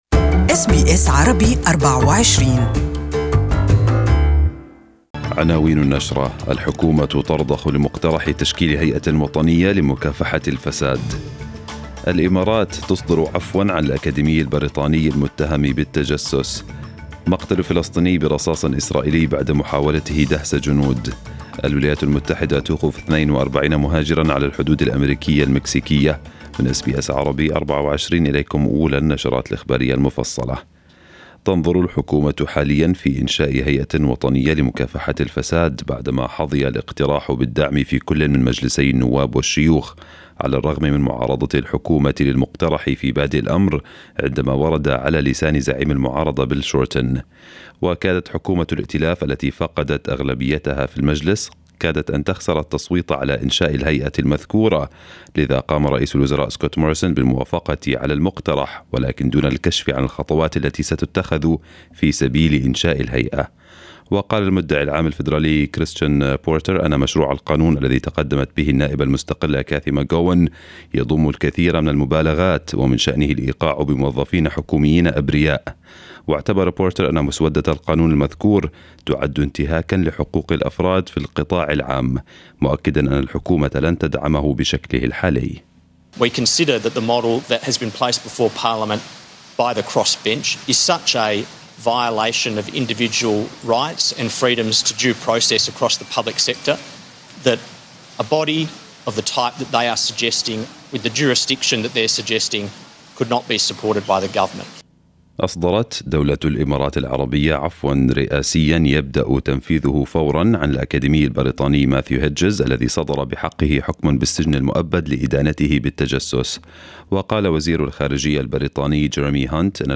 News bulletin of the day